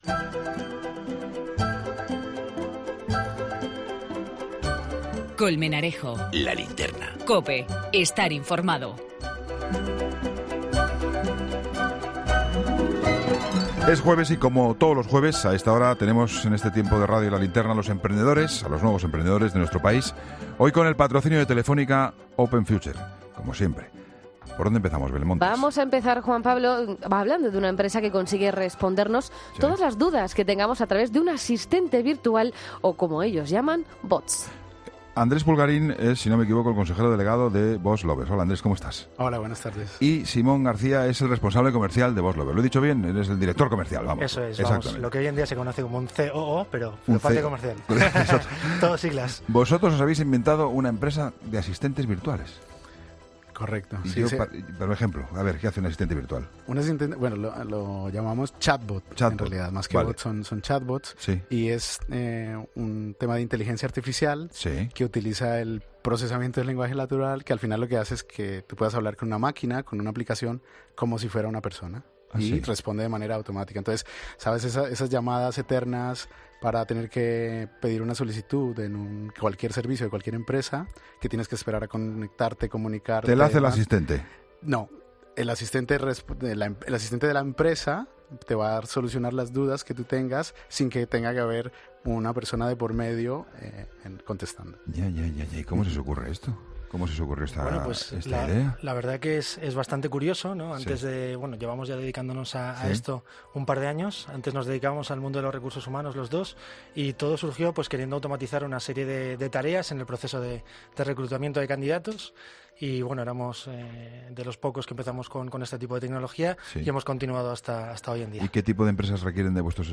en los estudios de COPE